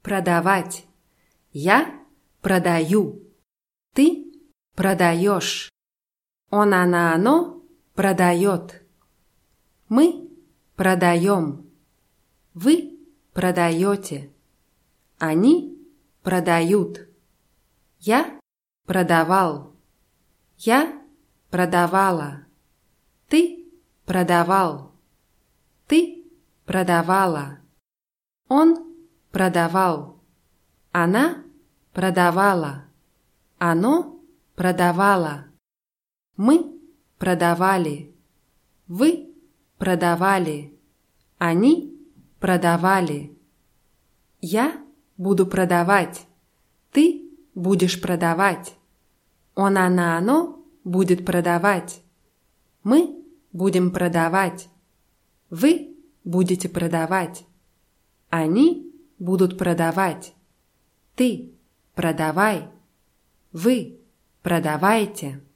продавать [pradawátʲ]